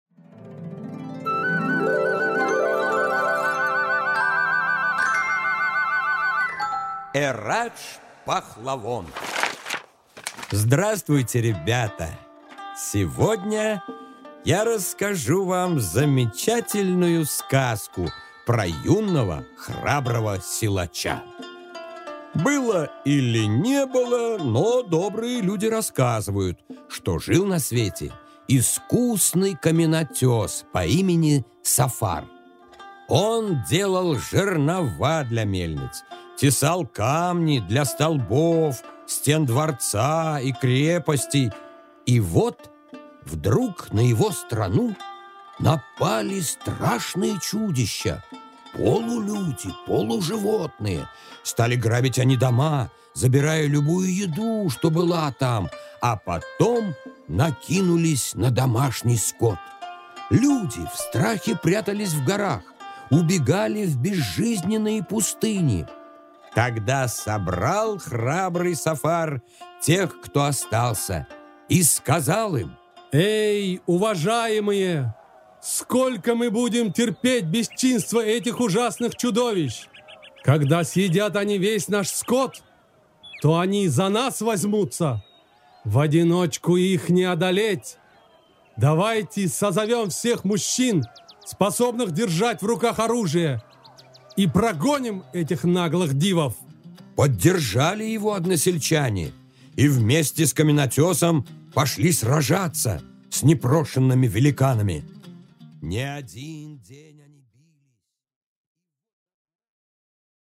Аудиокнига Эрадж-пахлавон